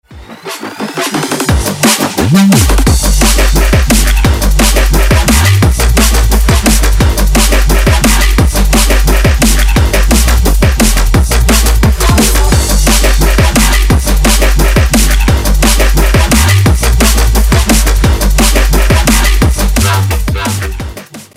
• Качество: 312, Stereo
жесткие
мощные
dance
качающие
techstep
Стиль: drum&bass